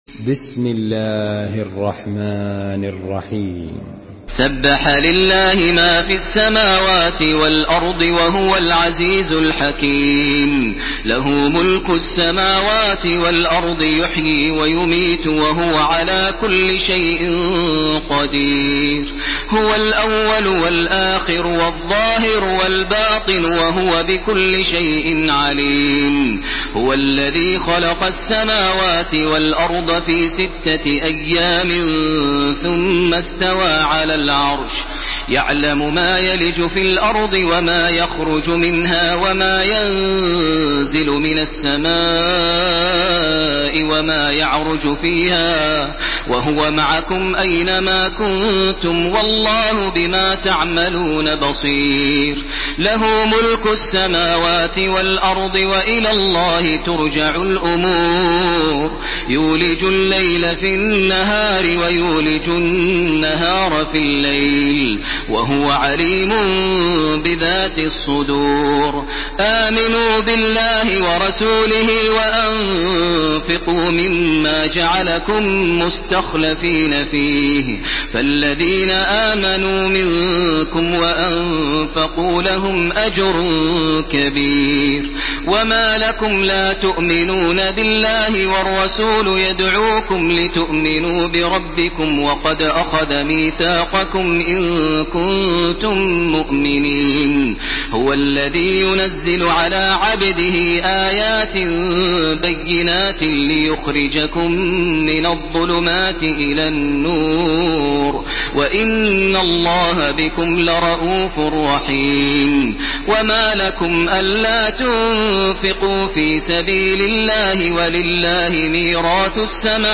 Surah Hadid MP3 by Makkah Taraweeh 1431 in Hafs An Asim narration.
Murattal